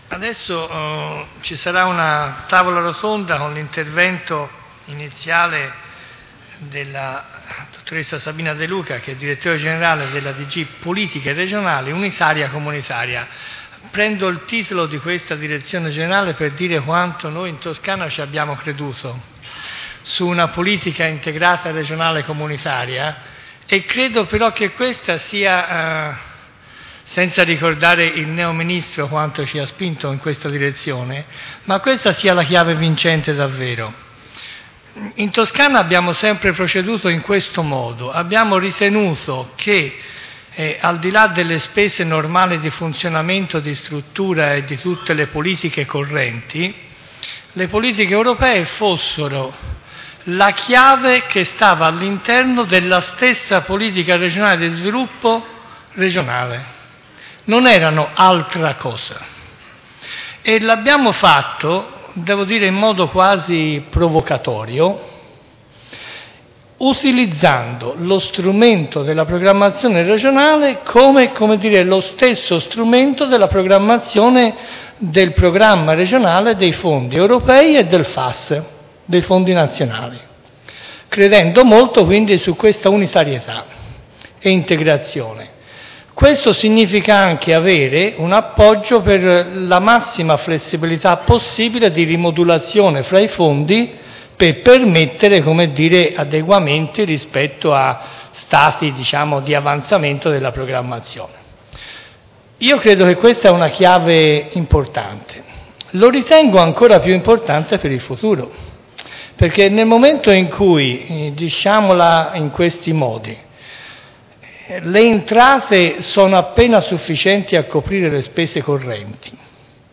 presentazione Tavola rotonda